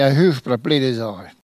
Elle crie pour appeler les oies
Langue Maraîchin